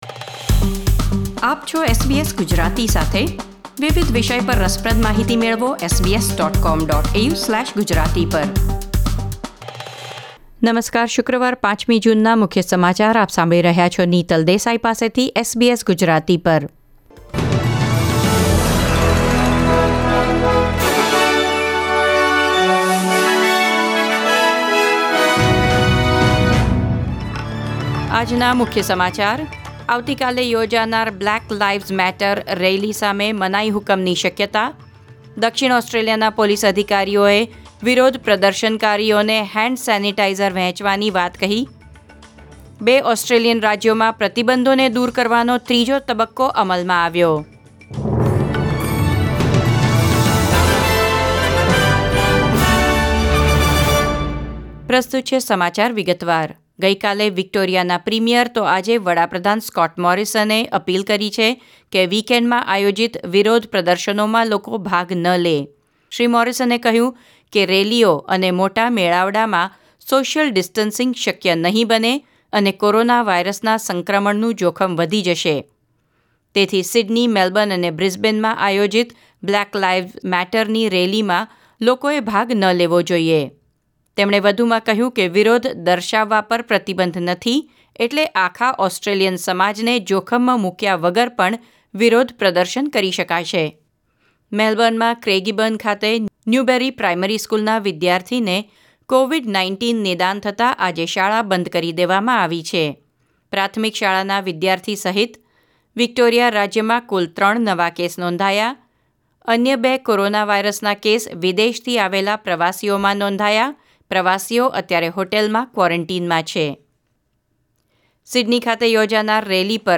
SBS Gujarati News Bulletin 5 June 2020